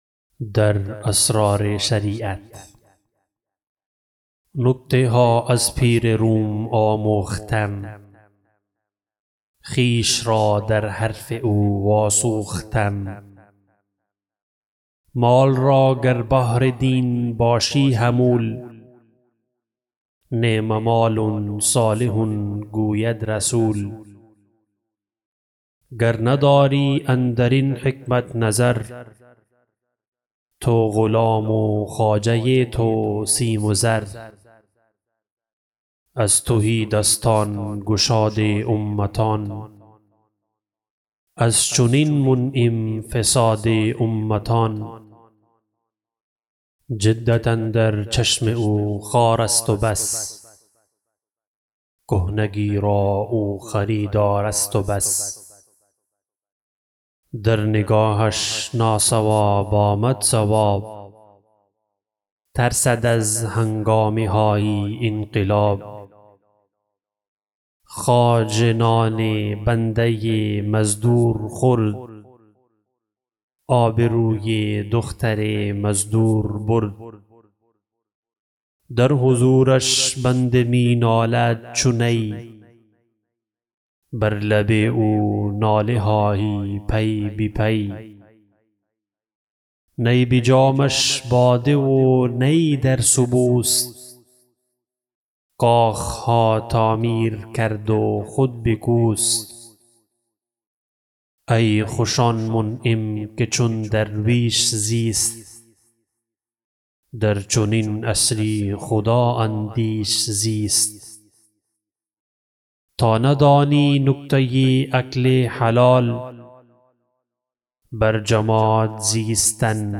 پس چه باید کرد؟ بخش ۹ - در اسرار شریعت به خوانش